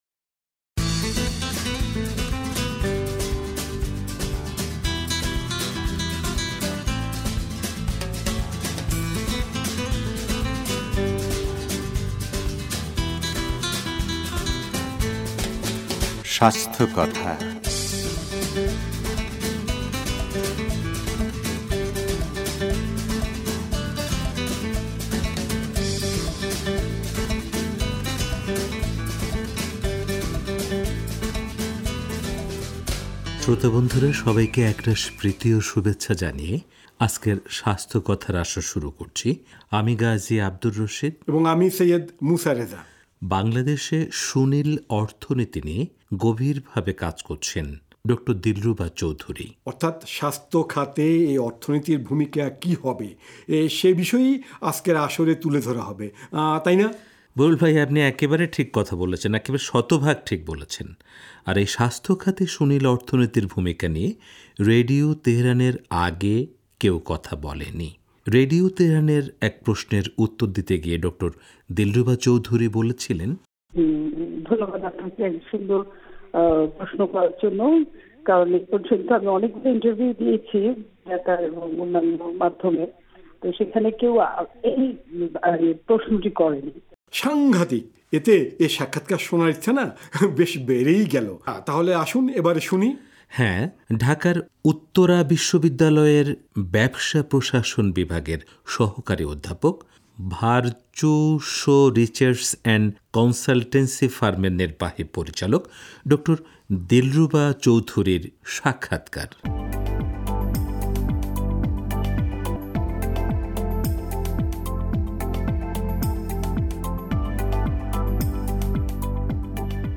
সাক্ষাৎকারভিত্তিক